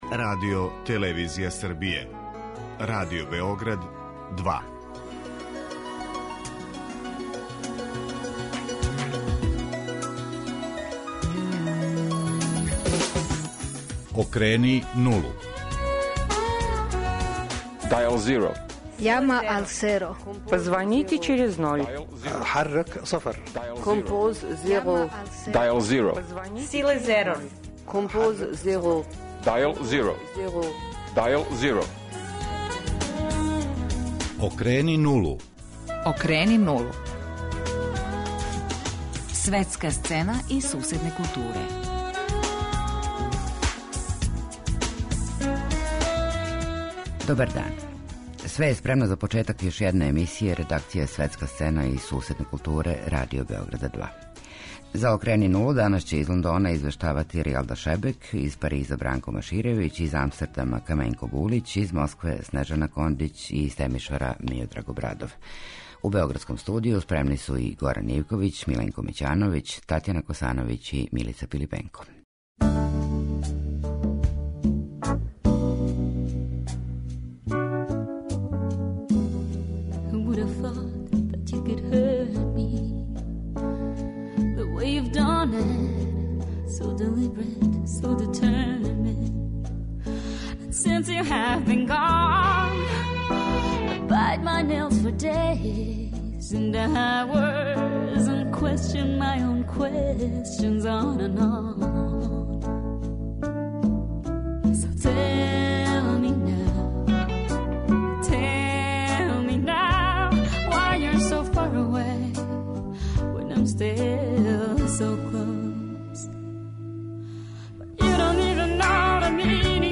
Догађаје на културним сценанама Велике Британије, Француске, Холандије, Русије и Румуније пратили су, протекле недеље, дописници РБ2.